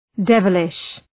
{‘devəlıʃ}